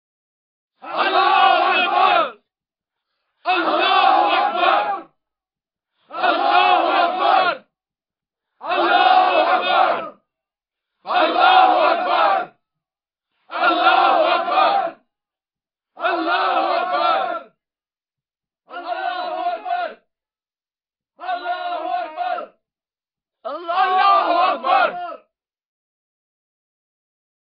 Звук кричащих арабов с возгласами Аллаху Акбар (Аллах велик)